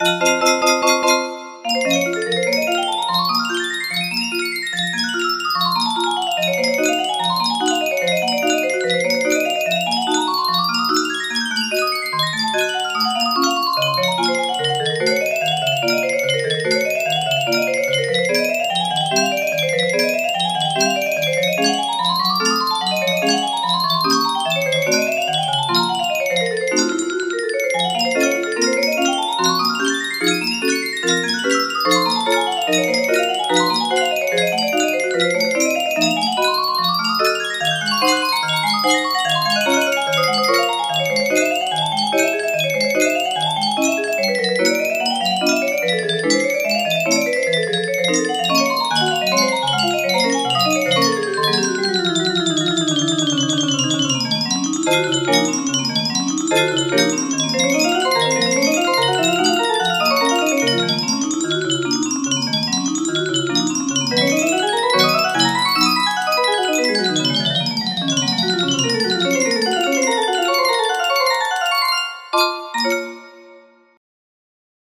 Chopin Prelude Op. 28, No. 16 music box melody
Full range 60